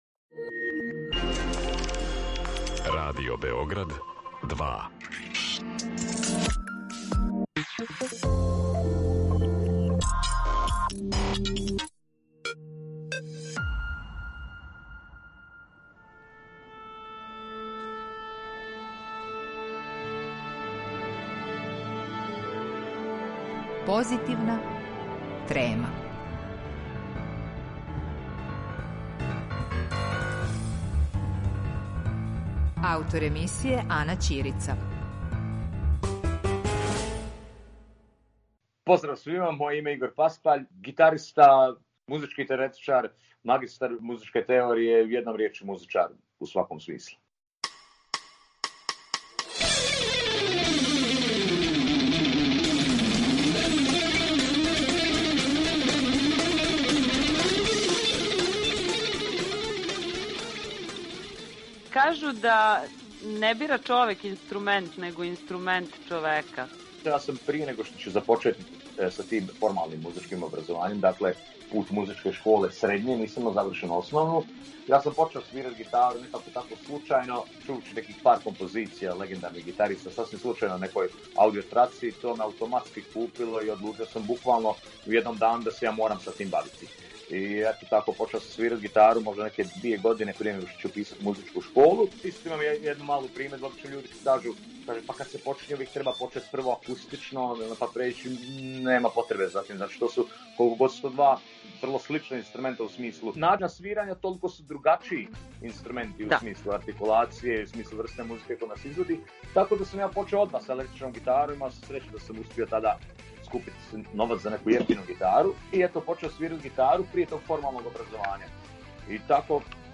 Музички теоретичар и уметник на електричној гитари